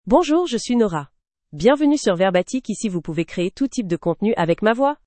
FemaleFrench (Canada)
Nora is a female AI voice for French (Canada).
Voice sample
Listen to Nora's female French voice.
Nora delivers clear pronunciation with authentic Canada French intonation, making your content sound professionally produced.